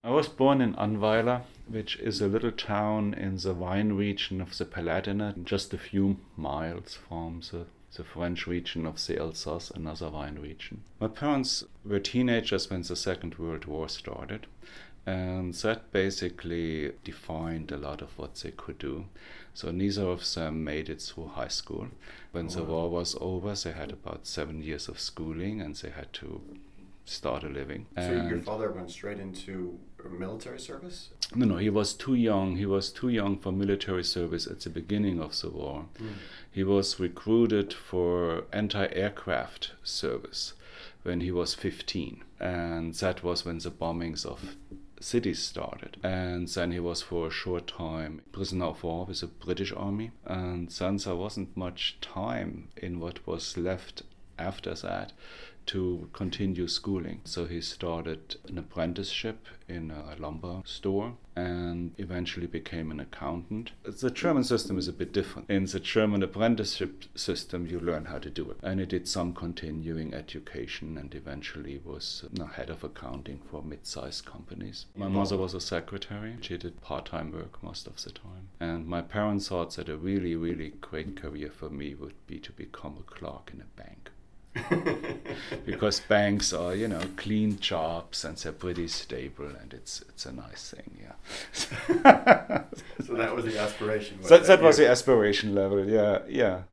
In this first excerpt, Dr. Schwarz describes a little about his parents and the region in which he grew up in southwestern Germany (about 1:30). As he describes, if everything had gone according to his parent’s plan, he might have eventually become a bank clerk!